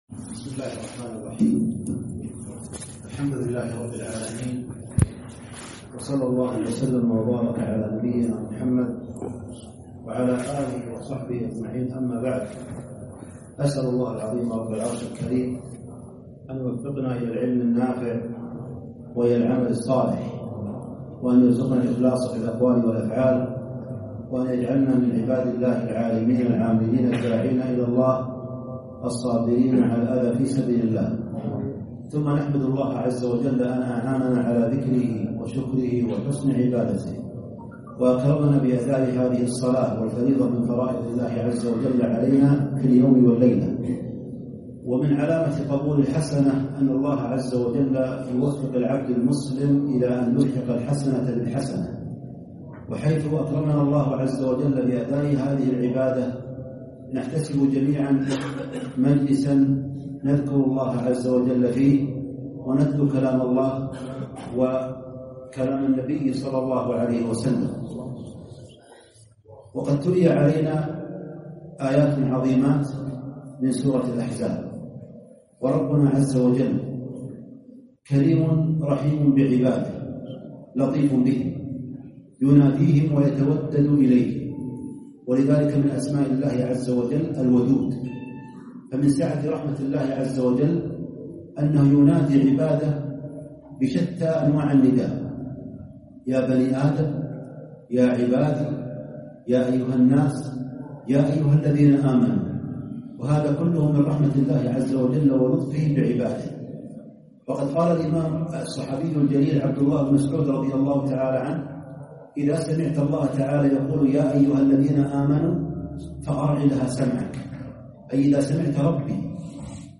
نعمة الإسلام - كلمة